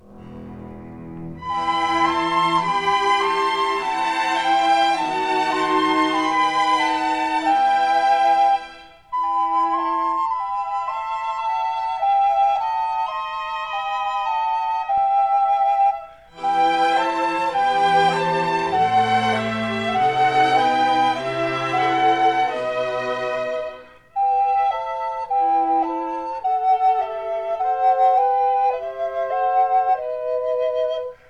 (recorders)
1960 stereo recording made by